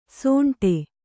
♪ sōṇṭe